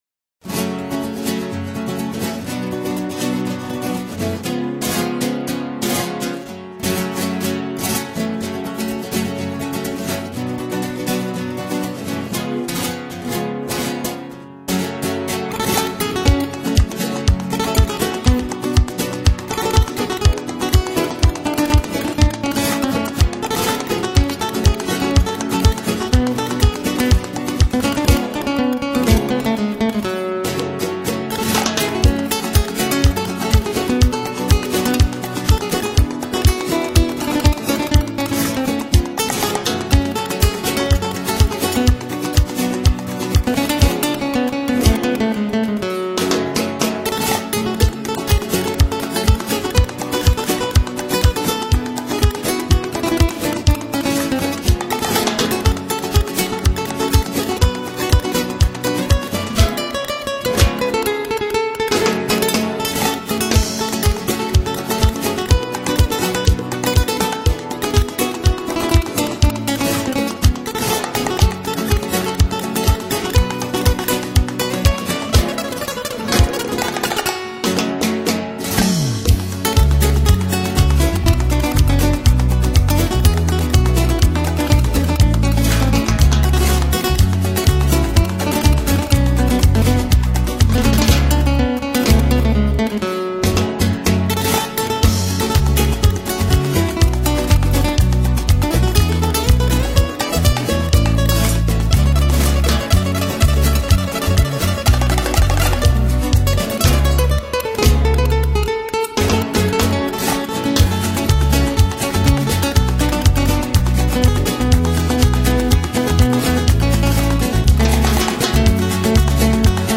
【阿根廷音乐】
利斯南区古老的街巷，仿佛在欣赏原汁原味的探戈舞曲。
随着那跳动的音符=激情的旋律，为他们的激情而振奋。